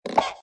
GUI_stickerbook_open.ogg